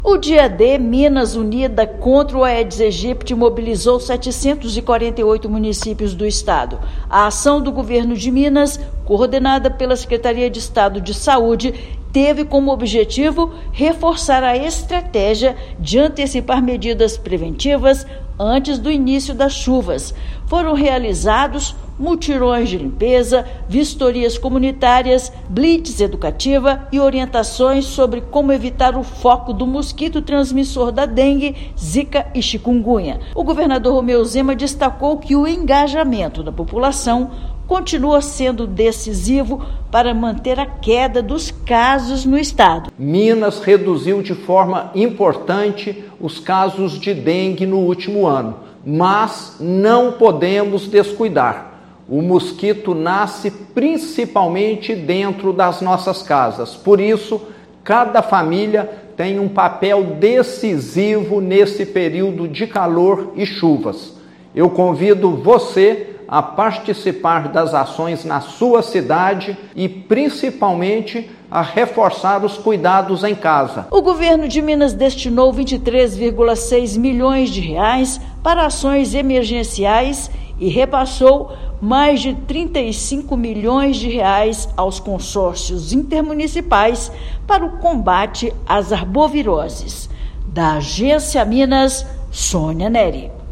Mutirões em quase 90% das cidades foram realizados, antes do período sazonal das arboviroses. Ouça matéria de rádio.